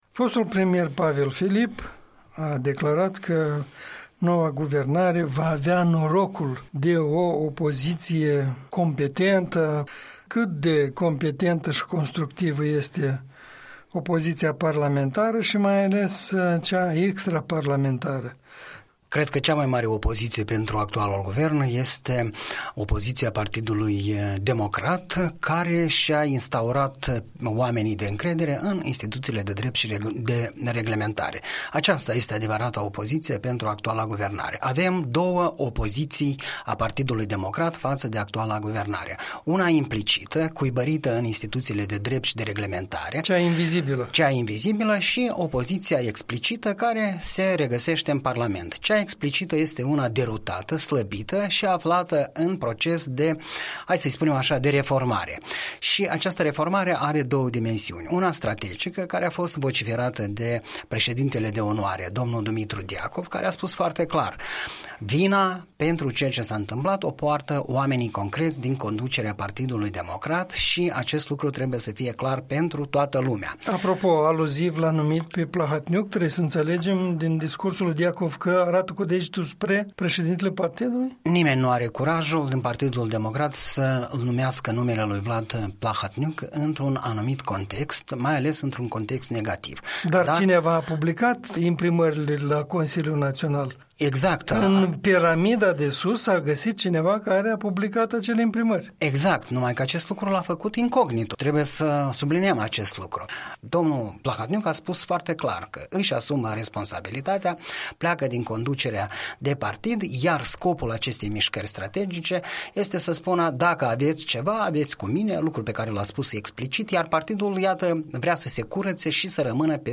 Comentariu săptămânal, în dialog la Europa Liberă, despre lipsa unei opoziții puternice la Chișinău și de ce acest lucru poate fi îngrijorător.